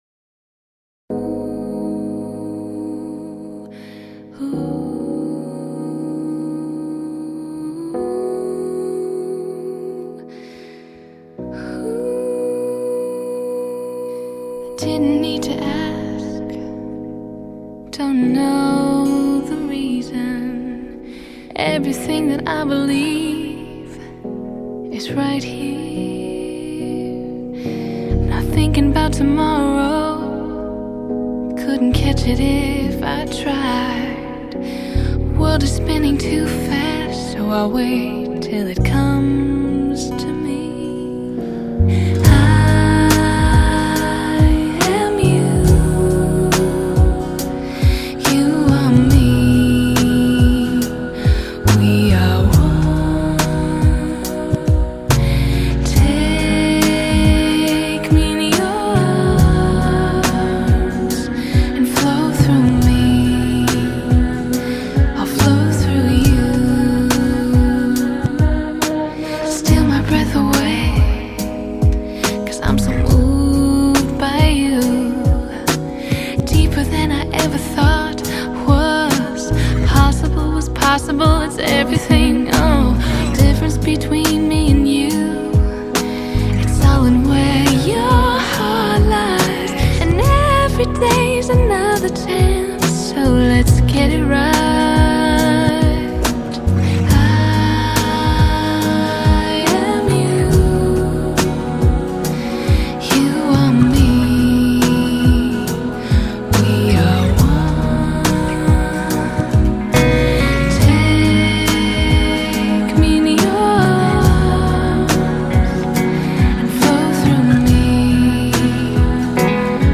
细腻高雅唯美
这张专辑的语言包括英语，意大利语、法语、梵语，并且加入了爵士和古典等元素，因此，它拥有在当今流行乐坛少见的深度和高雅。